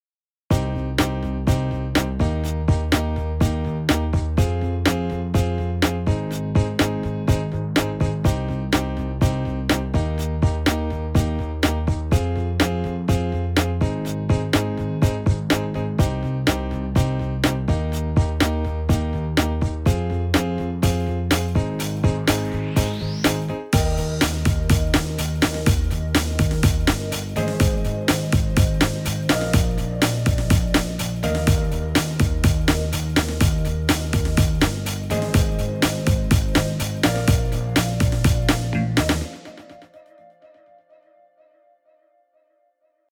in Eb karaoke